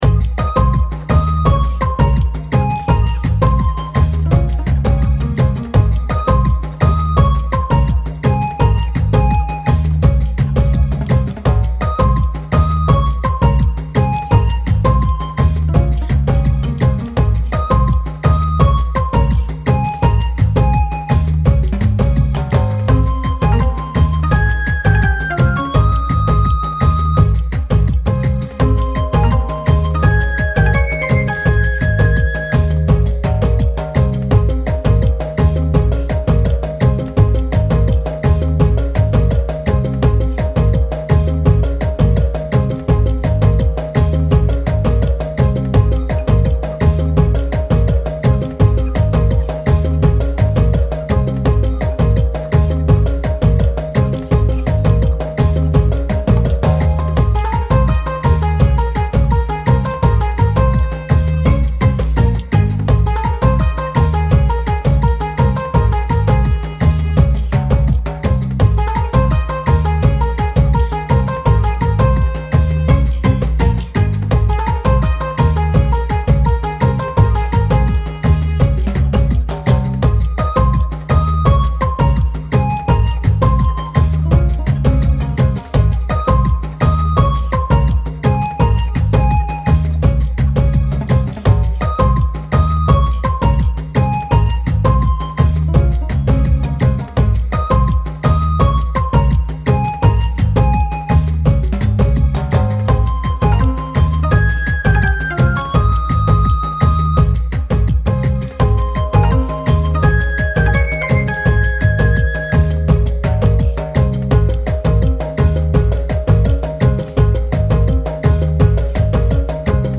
タイトルどおり南国やビーチを感じさせるアップテンポでノリノリなBGMです。 晴れやかで明るい雰囲気を醸し出しています。